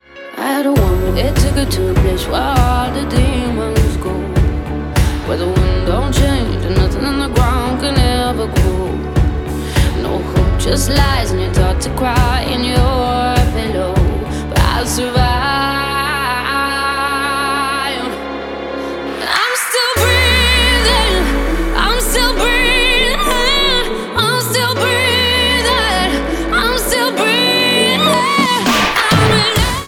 • Pop
Musically, it is a midtempo pop and synth-pop song.